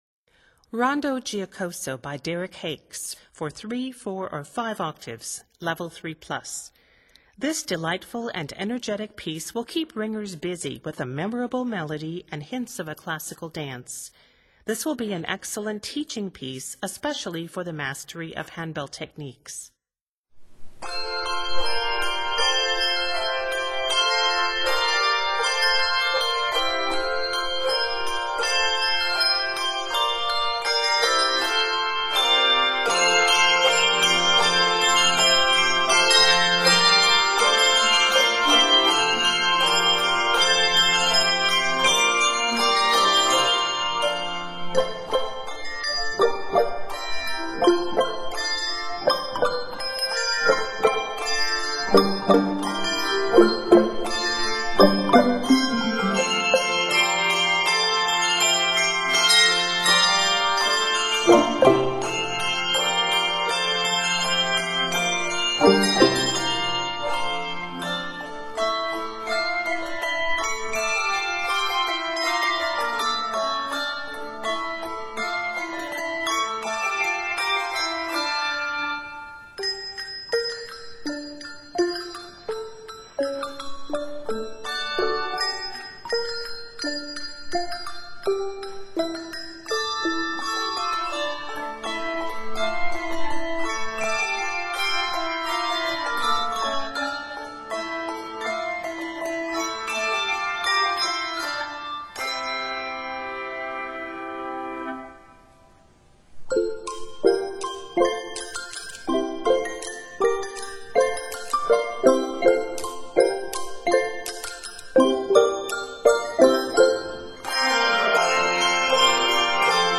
Set in F Major, it is 74 measures.